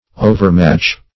Overmatch \O`ver*match"\, v. t.